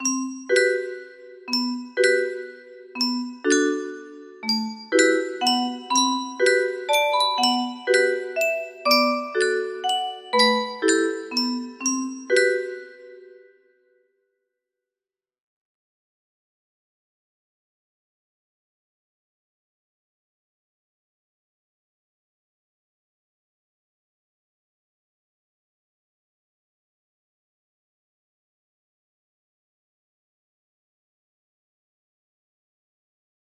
akijuhana music box melody